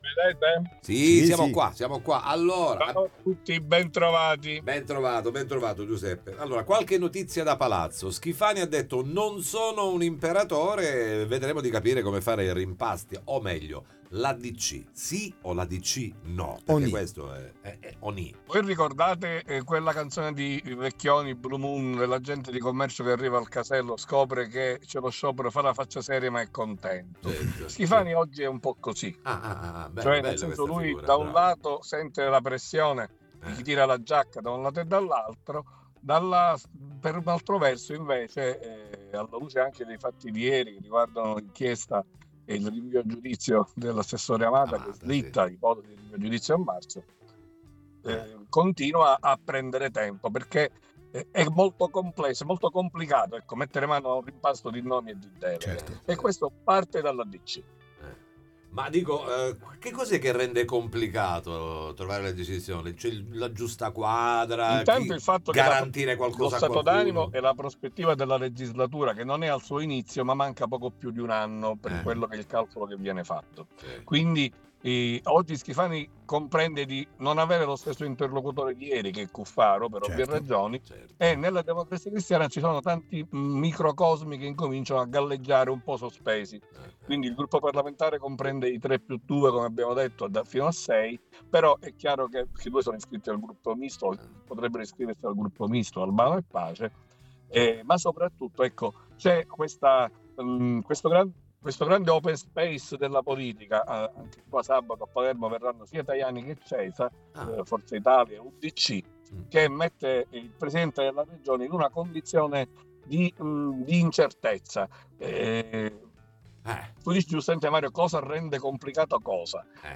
Schifani favorevole al rimpasto in giunta Interviste Time Magazine 14/01/2026 12:00:00 AM